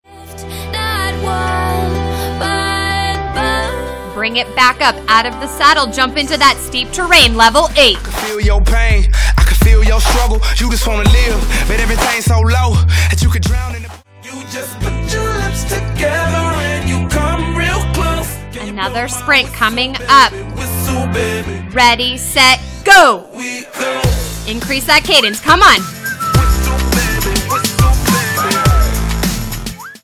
This class is filled with hill drills that will make your legs feel the burn. Note: Songs contain very little explicit language.